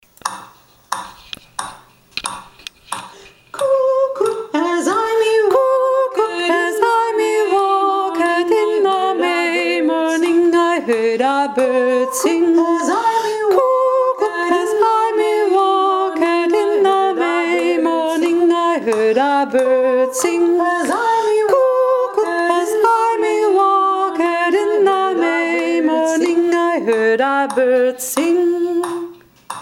JA hier findet ihr die Aufnahmen unserer Jodler , von mir eingesungen.
Renaissancelied England (Noten pdf)
Kanon 2 Stimmen